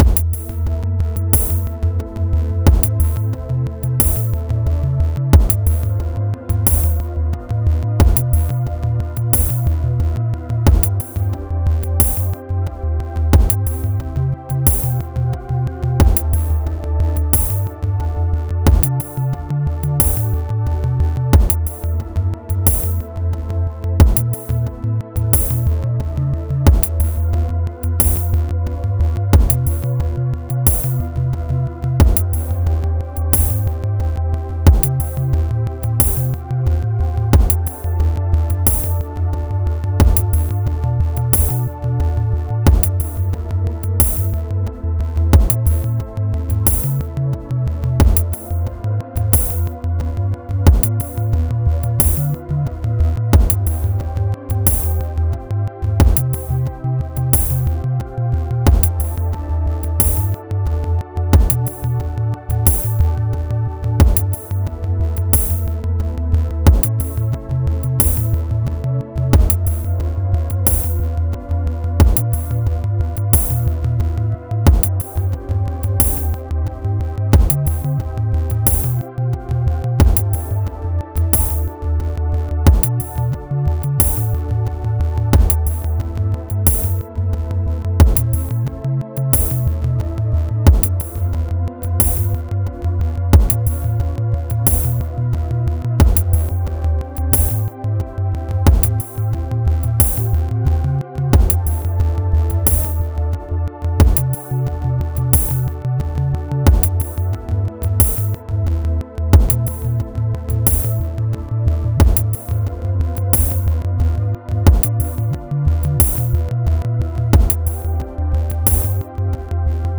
Yes it's the same chord progression that's being altered by automation. This is a microtonal piece built from a simple dorian chord progression. It still sounds a little too much like pitch bends but I got it as good as I am going to get.
So while the chord structure stays the same no two cycles are the same. It goes for 60 cycles.